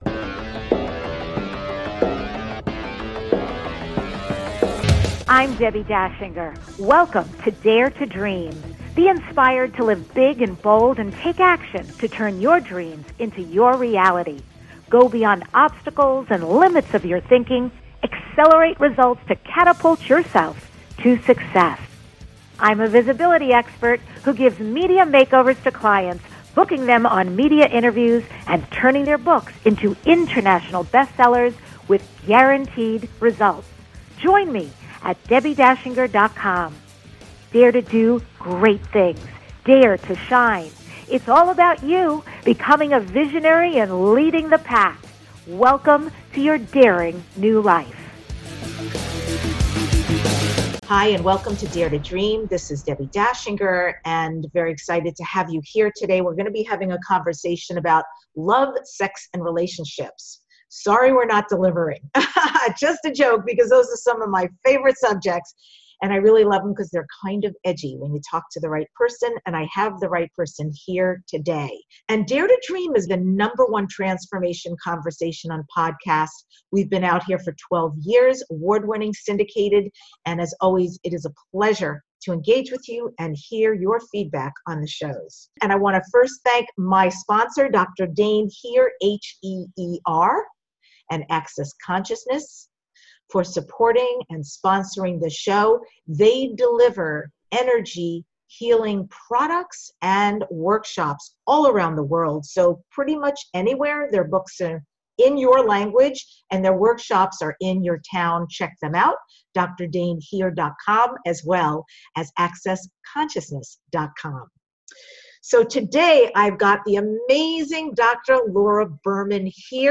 Guest, Dr Laura Berman